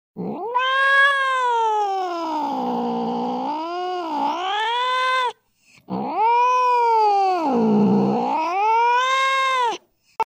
На этой странице собраны звуки кошачьих драк – от яростного шипения до громких воплей и топота лап.
Рык кота перед нападением